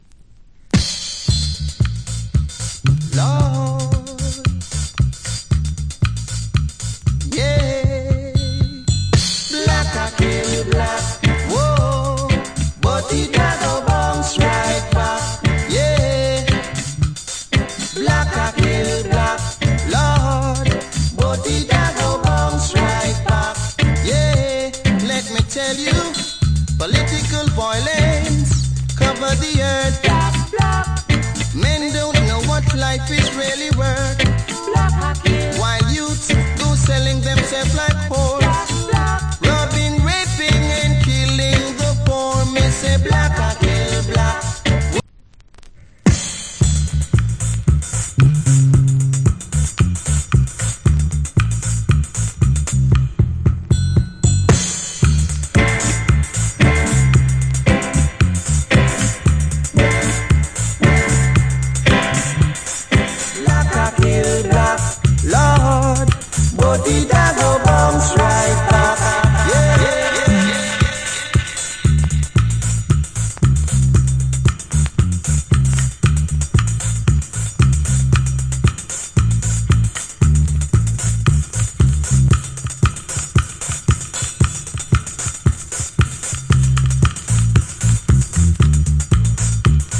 Good Roots Rock Vocal.